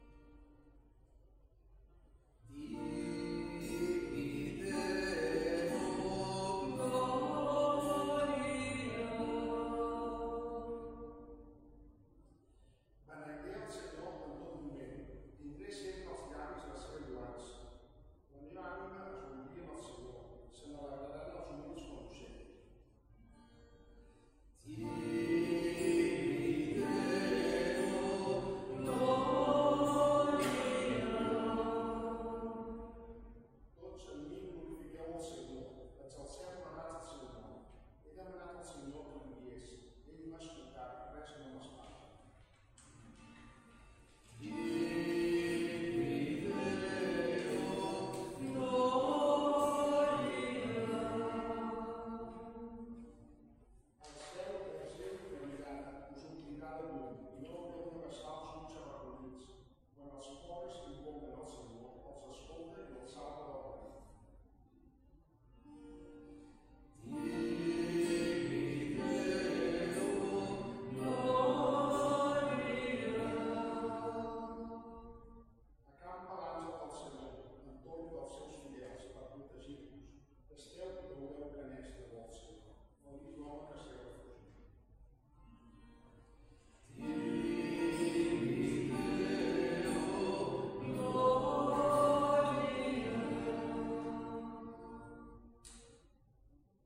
Església del Sagrat Cor - Diumenge 29 de juny de 2025
Vàrem cantar...